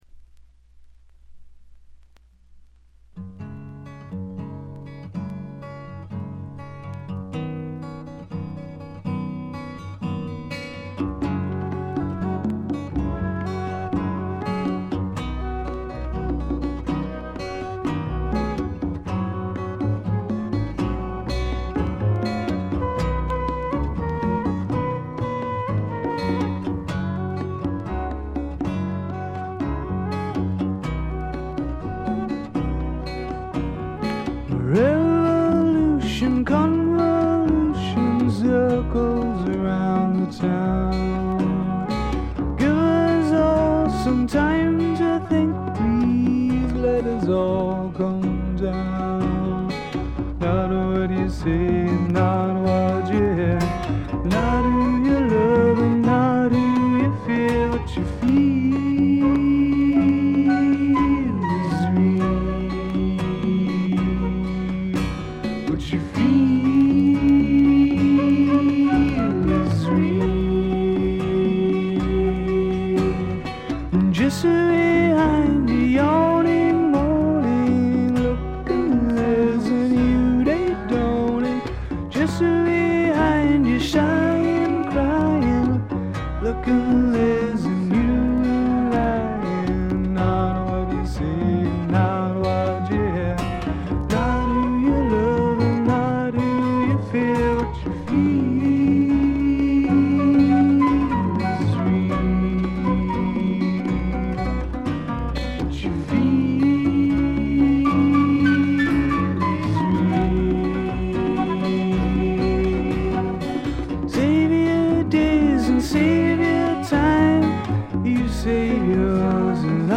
ホーム > レコード：英国 SSW / フォークロック
試聴曲は現品からの取り込み音源です。